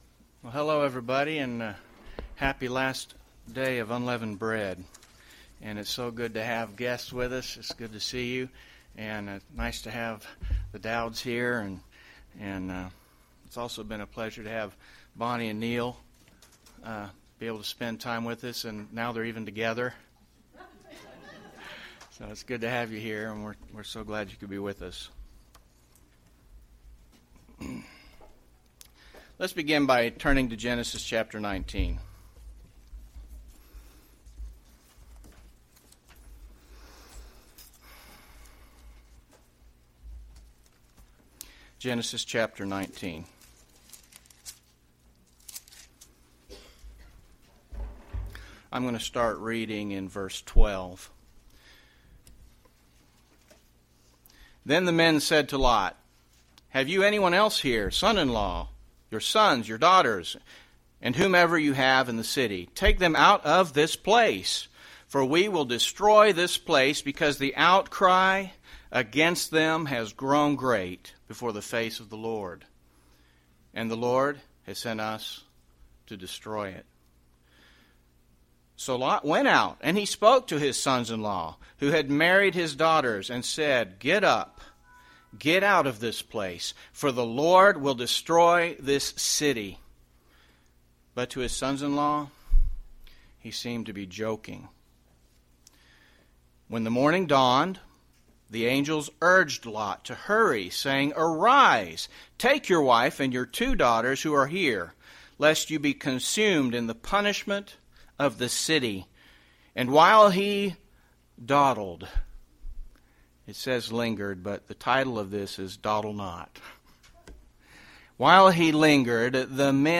UCG Sermon Obedience to God Loving God worshiping god follow God Notes PRESENTER'S NOTES Dawdle NOT!